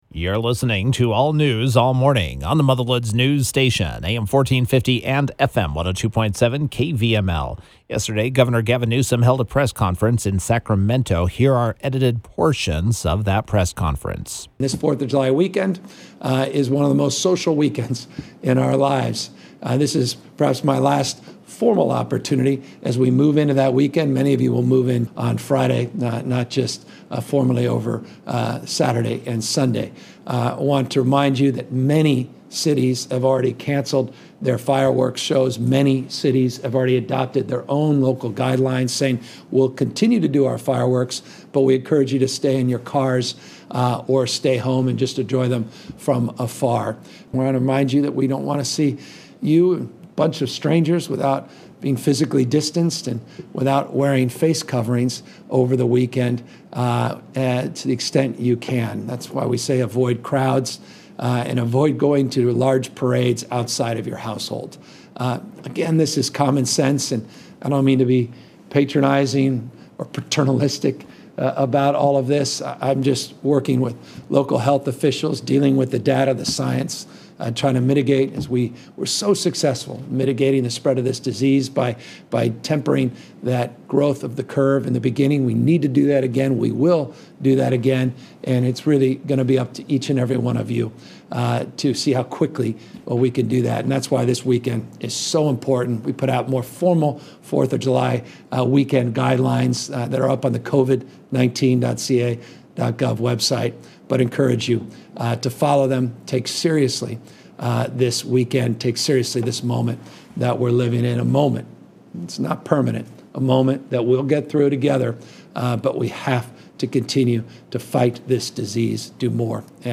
Gov. Gavin Newsom was Friday’s KVML “Newsmaker of the Day”.